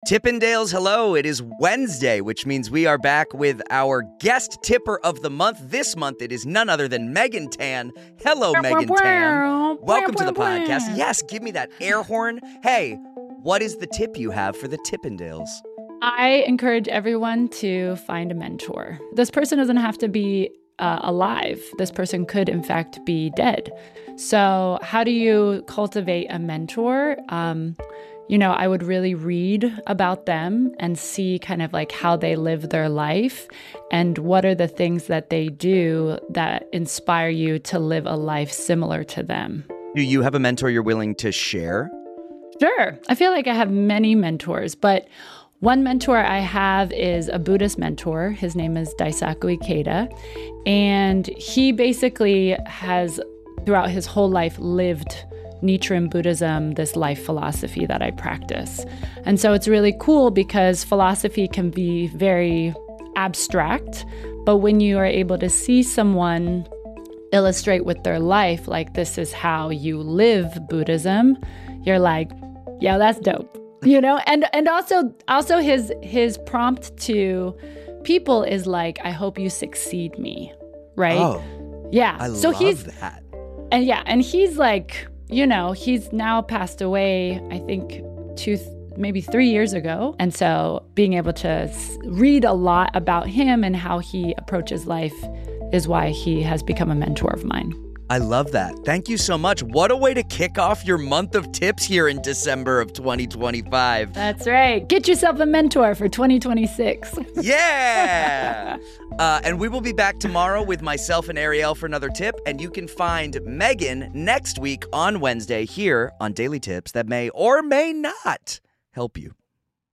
Guest tipper